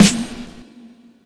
Snares
Medicated Snare 11.wav